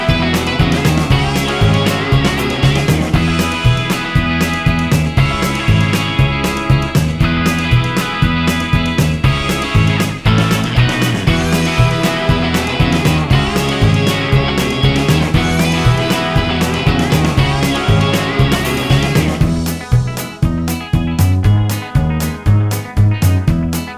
no Backing Vocals Rock 'n' Roll 3:46 Buy £1.50